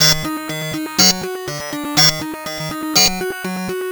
Off The Hook Eb 122.wav